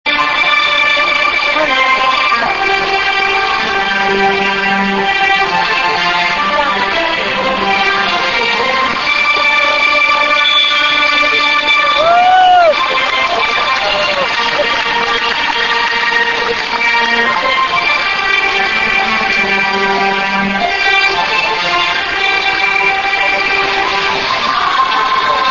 На шоу Чжан Имоу, Яншо
Само представление — с одной стороны несколько тысяч зрителей, с другой несколько тысяч участников. Большой водоем, в котором, по сути, все и происходит, огни, музыка, слаженные движения в обрамлении тех самых знаменитых гор.